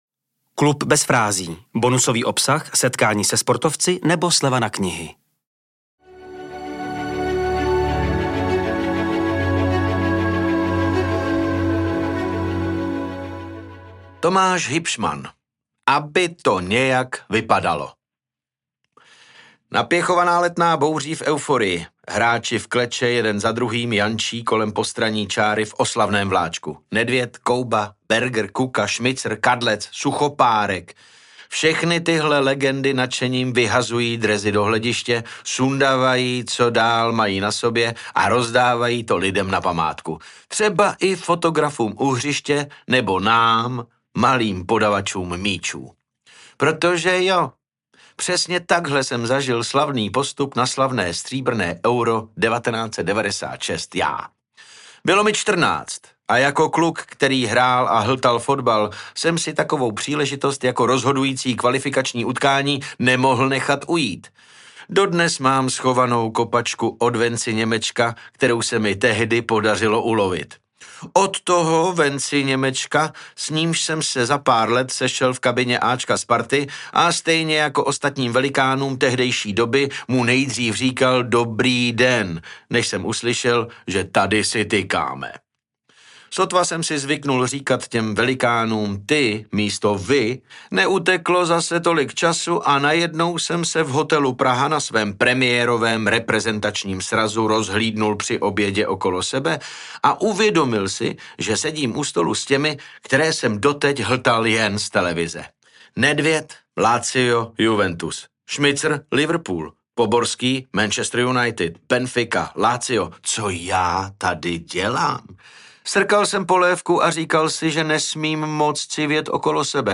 Užijte si jeho příběh, který namluvil David Novotný .